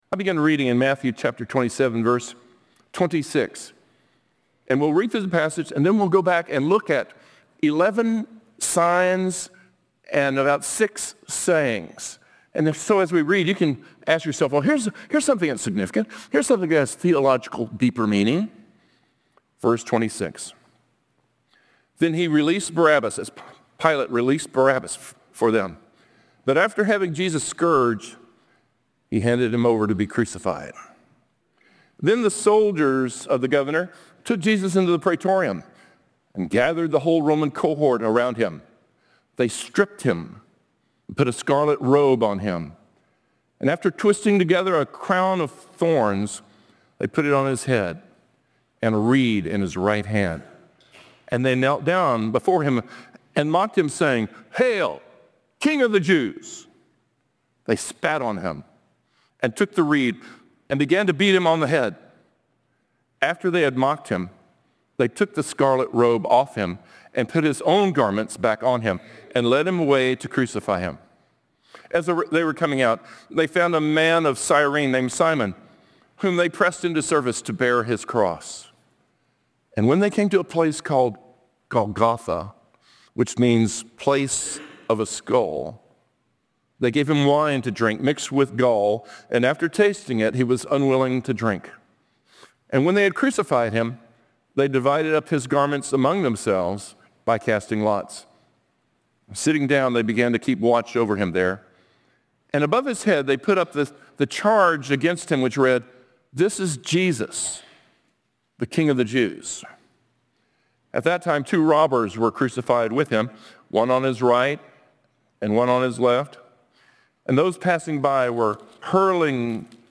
A message from the series "Worship Matters."